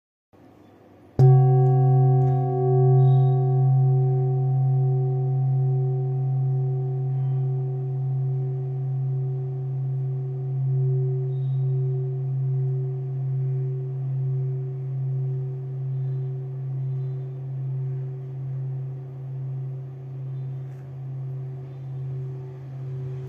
Buddhist Hand Beaten Kopre Singing Bowl, with Antique, Old
Material Bronze
It is accessible both in high tone and low tone .